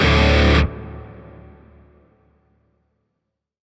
LotusShawzinPentMajChordB.ogg